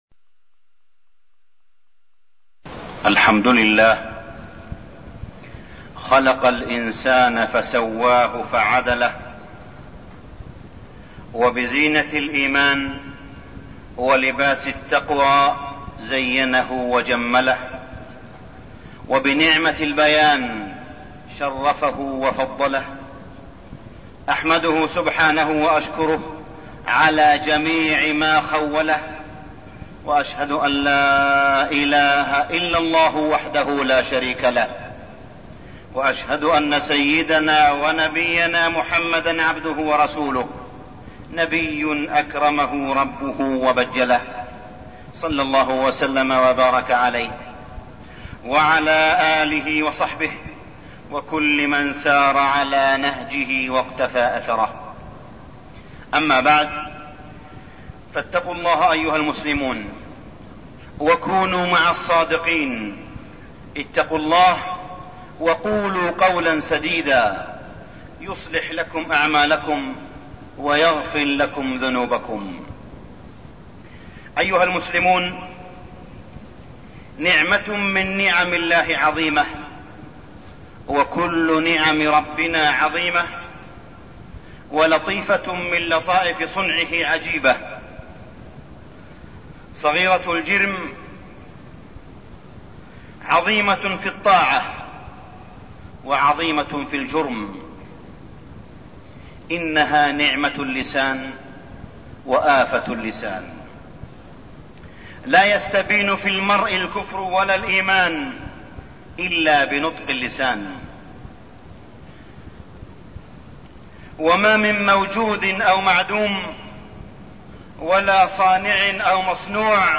تاريخ النشر ١ جمادى الآخرة ١٤١٥ هـ المكان: المسجد الحرام الشيخ: معالي الشيخ أ.د. صالح بن عبدالله بن حميد معالي الشيخ أ.د. صالح بن عبدالله بن حميد آفات اللسان